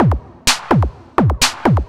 DS 127-BPM B3.wav